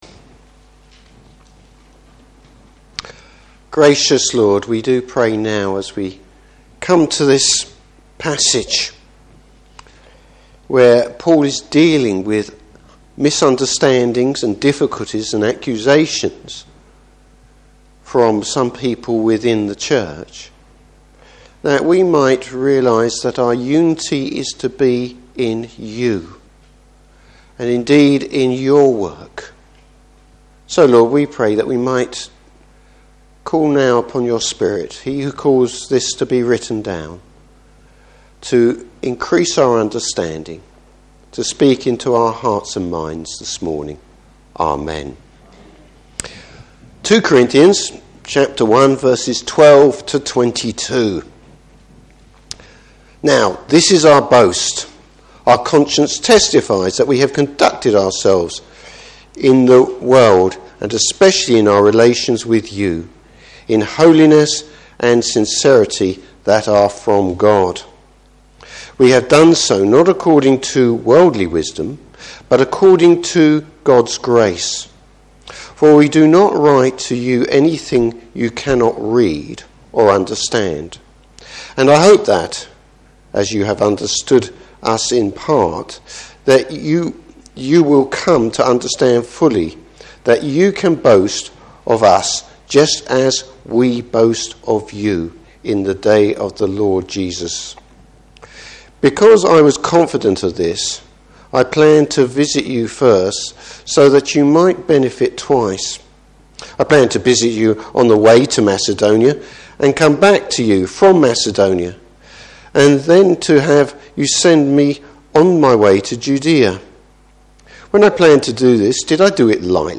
Service Type: Morning Service God guarantees his peoples future.